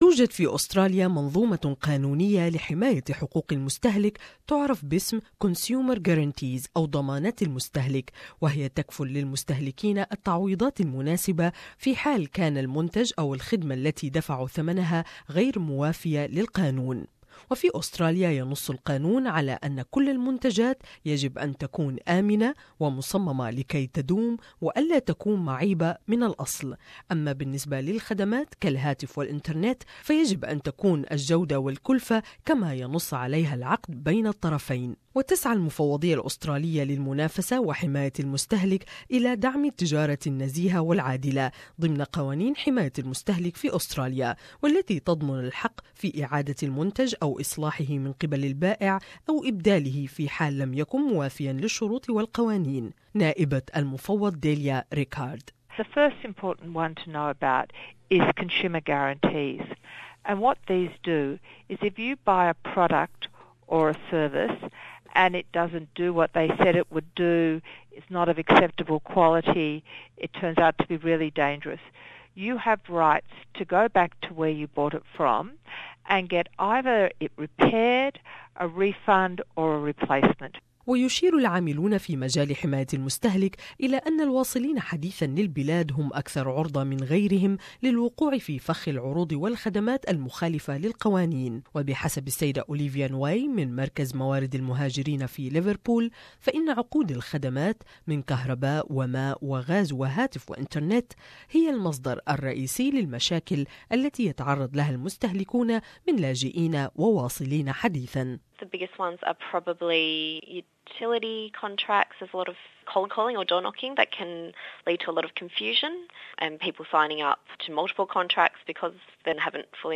In Australia consumers have rights under the law called consumer guarantees - and they could save you money.However, people whove recently settled in Australia can be extra vulnerable to questionable deals and businesses. More in this report